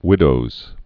(wĭdōz)